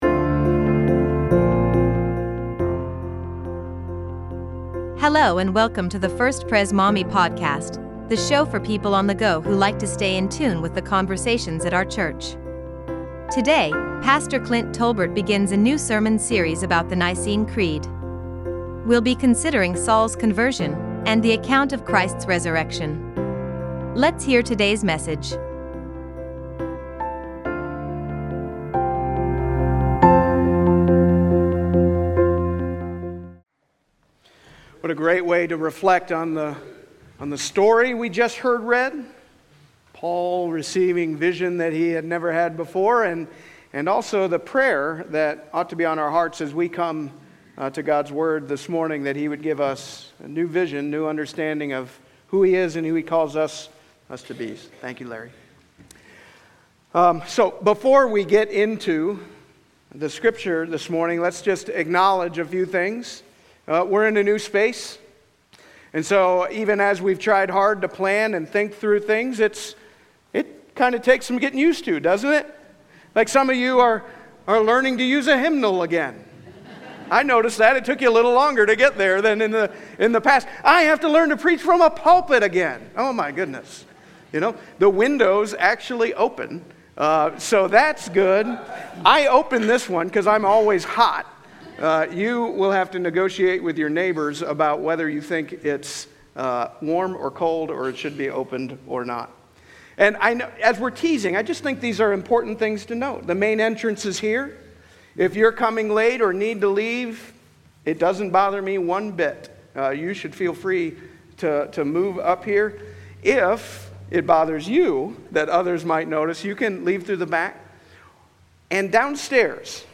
Presbyterian church in Maumee, Ohio, USA.